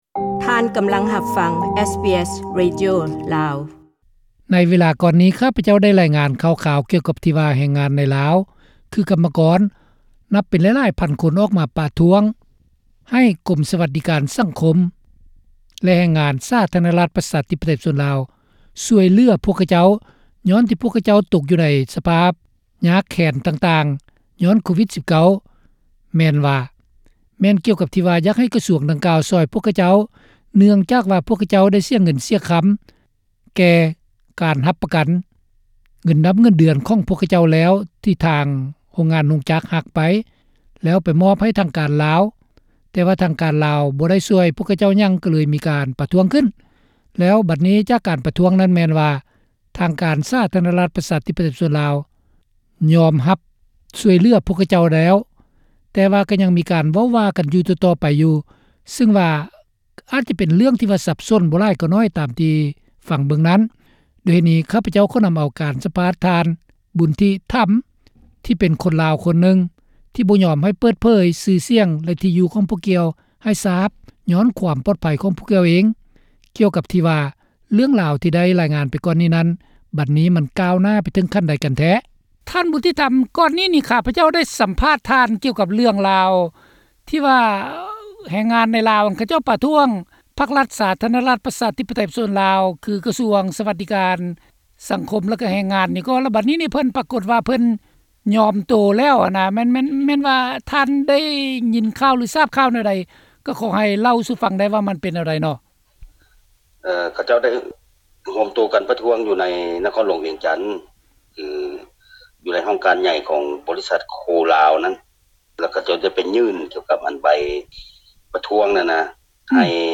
ຣັຖບານ ສປປລາວ ລົງຄໍໃຫ້ແຮງງານທີປະທ້ວງແລ້ວ (ສຳພາດ)